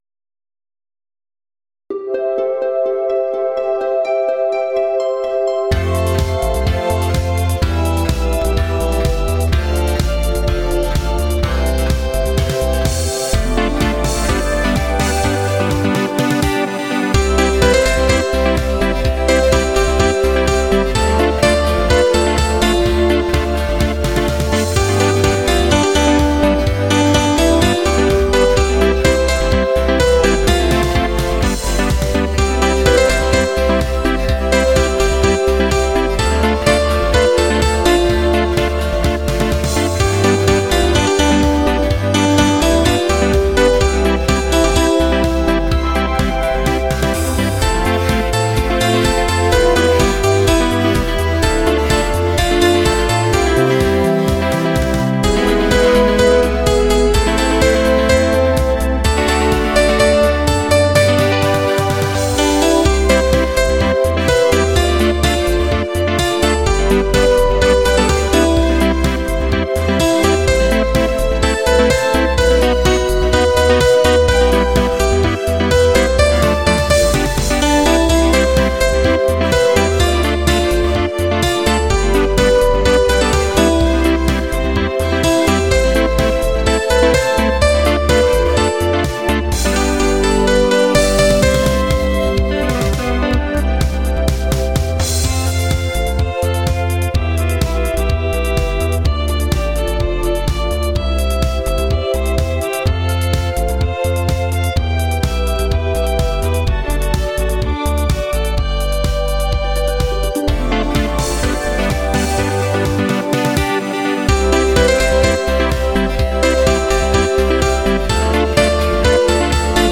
◆作詞なしインストゥメンタル曲 ※楽譜用意できます。